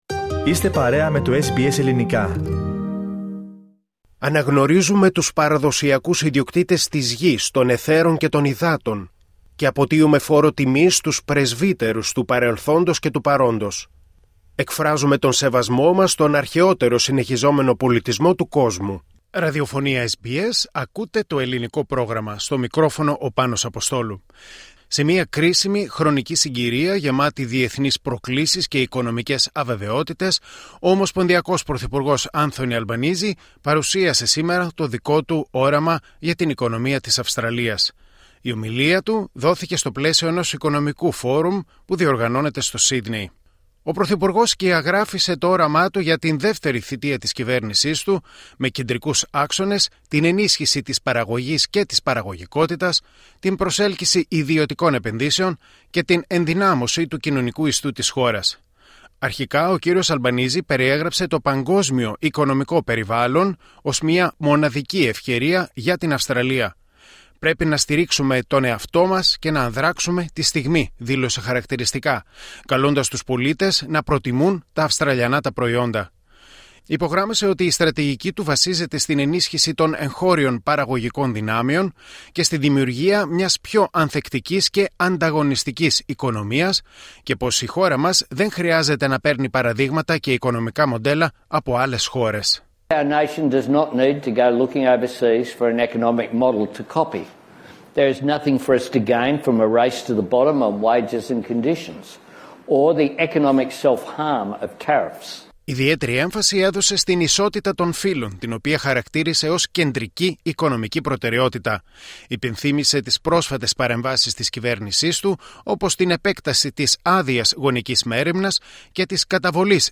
Σε μια κρίσιμη χρονική συγκυρία γεμάτη διεθνείς προκλήσεις και οικονομικές αβεβαιότητες, ο ομοσπονδιακός πρωθυπουργός Άνθονι Αλμπανήζι παρουσίασε το όραμά του για την οικονομία της Αυστραλίας. Η ομιλία του δόθηκε στο πλαίσιο ενός οικονομικού φόρουμ που πραγματοποιείται στο Σύδνεϋ